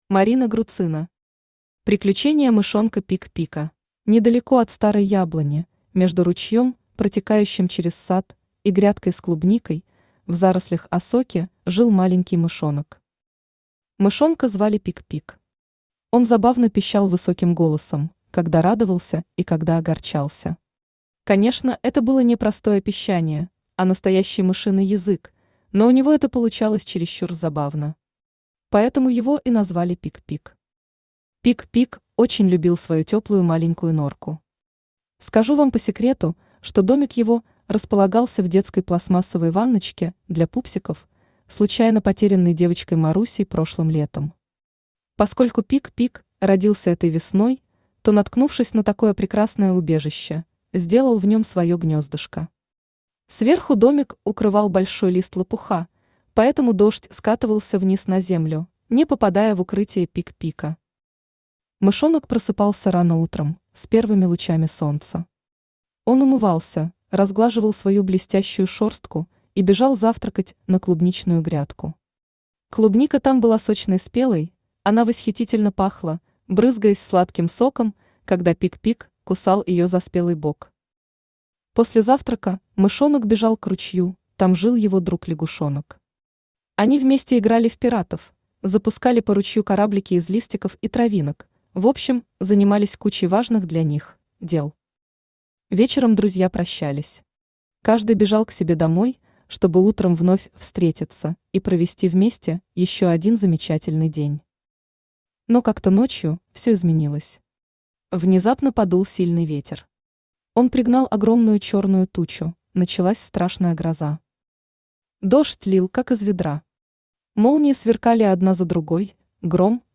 Аудиокнига Приключения мышонка Пик-Пика | Библиотека аудиокниг
Aудиокнига Приключения мышонка Пик-Пика Автор Марина Рудольфовна Груцина Читает аудиокнигу Авточтец ЛитРес.